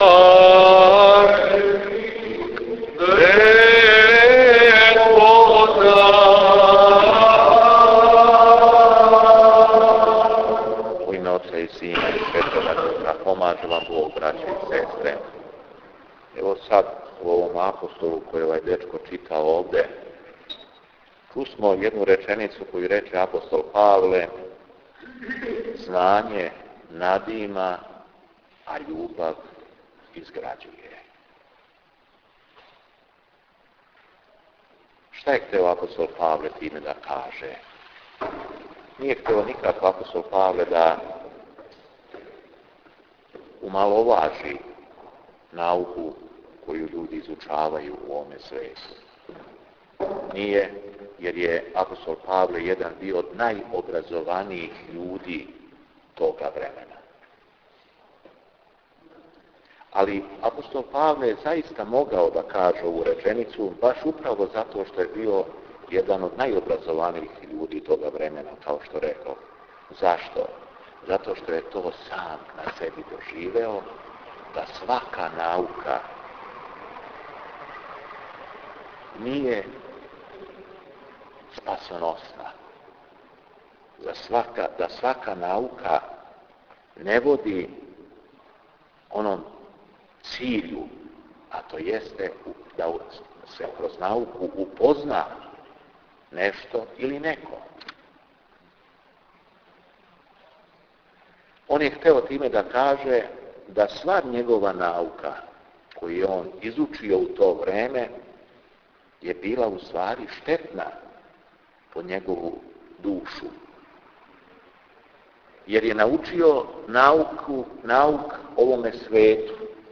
Беседа епископа Јована у Храму Свете Петке у насељу Виногради